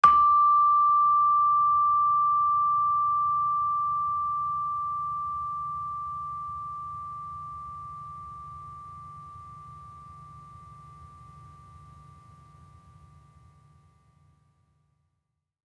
Gamelan Sound Bank
Gender-4-D5-f.wav